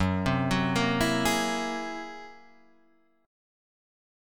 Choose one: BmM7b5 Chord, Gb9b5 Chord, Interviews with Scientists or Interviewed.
Gb9b5 Chord